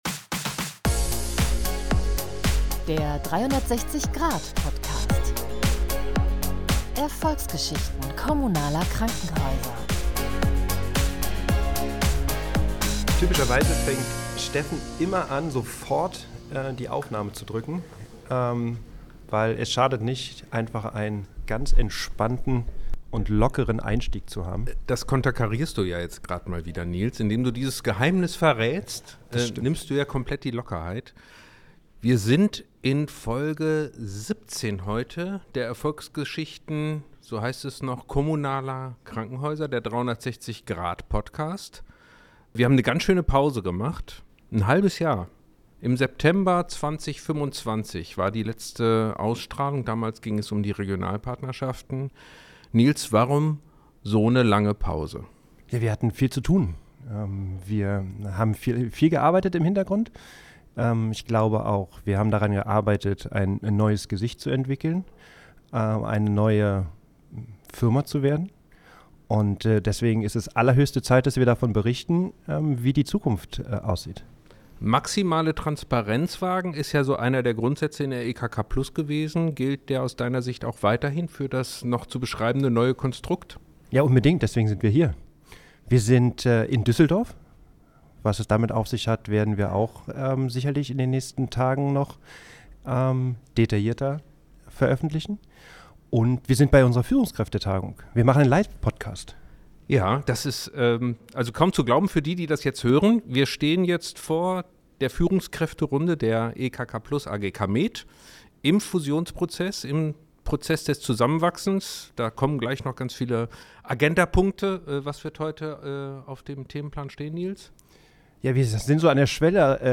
Die drei geben persönliche Einblicke in die Hintergründe der Zusammenarbeit, sprechen über gemeinsame Ziele, den neuen Standort in Düsseldorf und darüber, warum Transparenz und Vertrauen zentrale Erfolgsfaktoren auf diesem Weg sind. Ein Gespräch über Veränderung, Zusammenarbeit und die Zukunft der Einkaufsgemeinschaften im Gesundheitswesen.